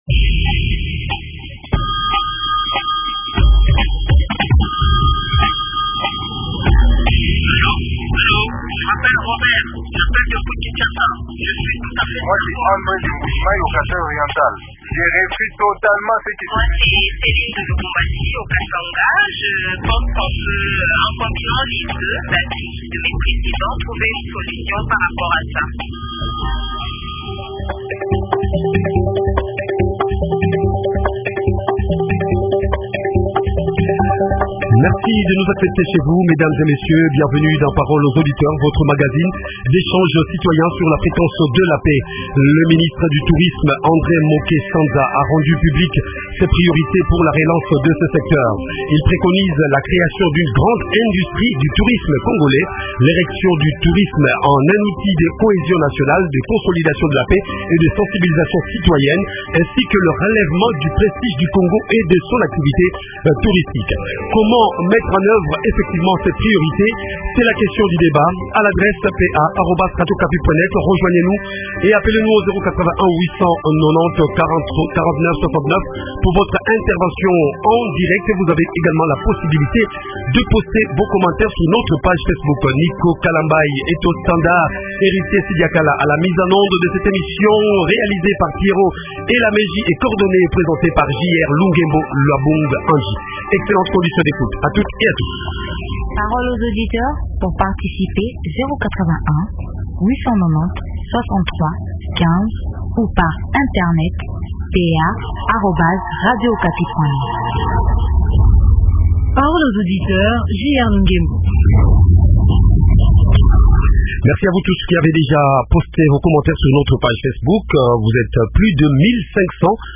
Invité: André Moke Sanza, ministre du Tourisme.